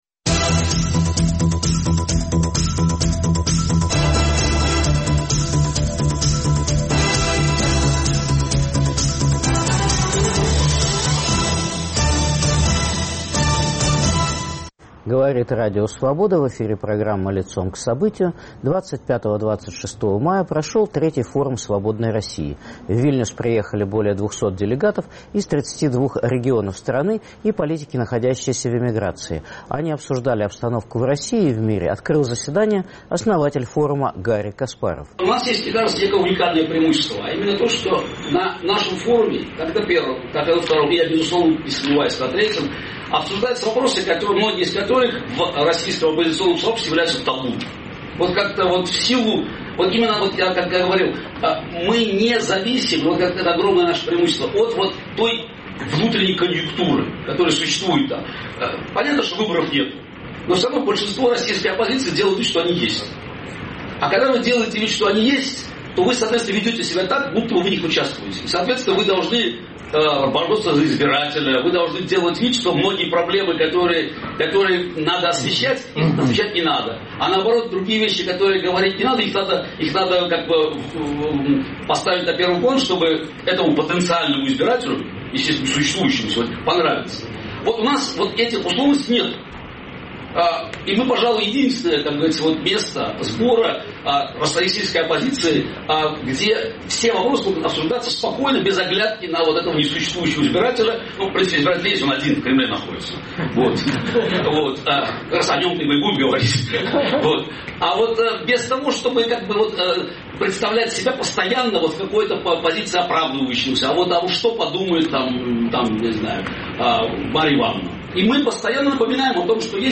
Форум свободной России, состоялся в Вильнюсе 25-26 мая 2017 г.